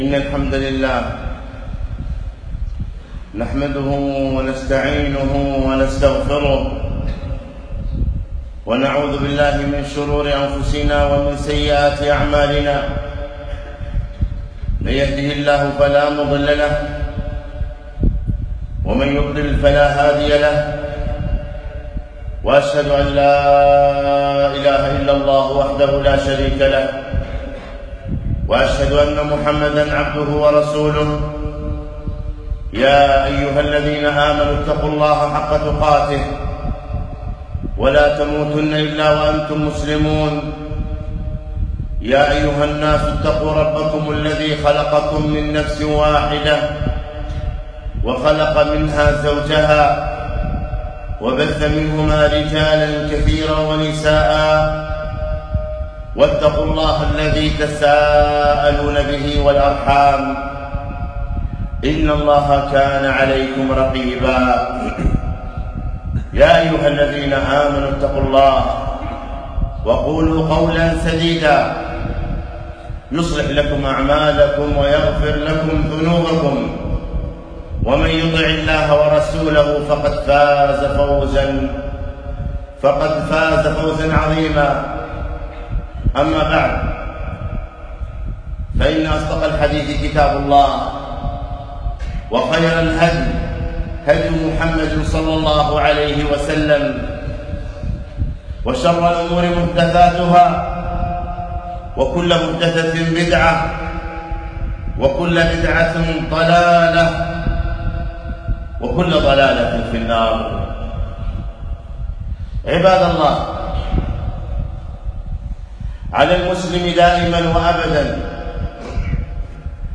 خطبة - أسباب الفلاح